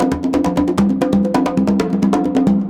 CONGABEAT9-R.wav